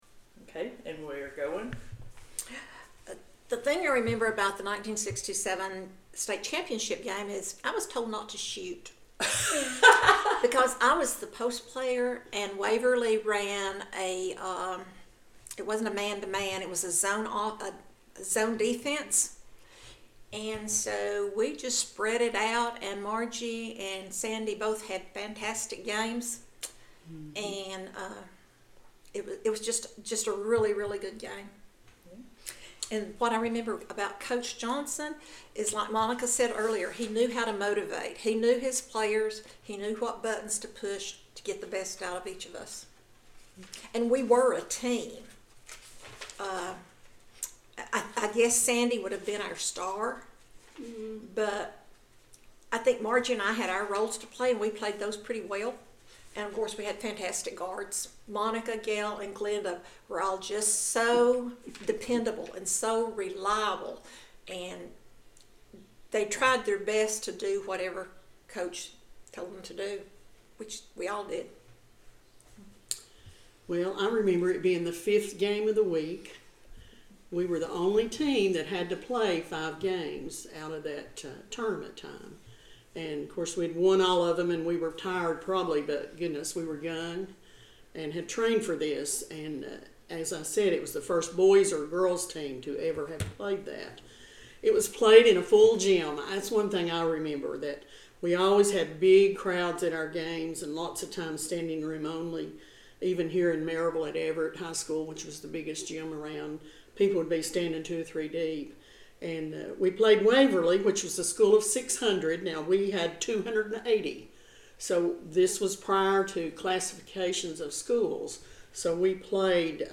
An Audio Interview